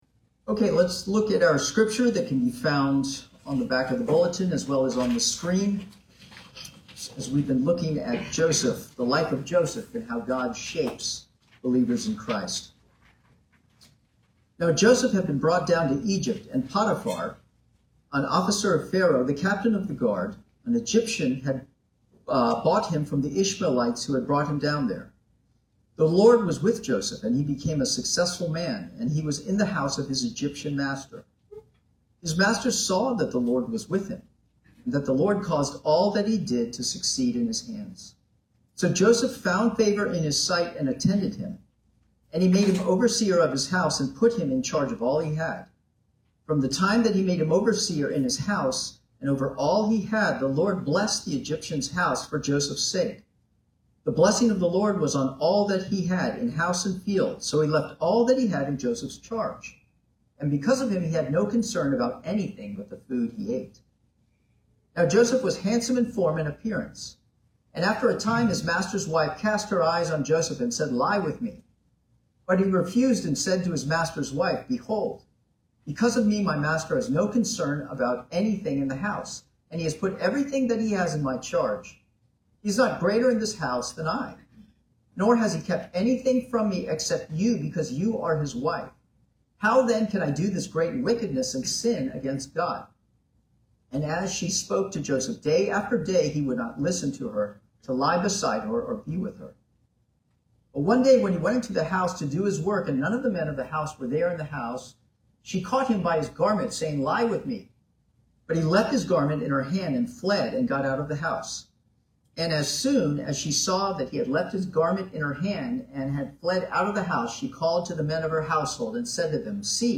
Sermons from Redeemer Presbyterian Church in Virginia Beach, VA.
Sermons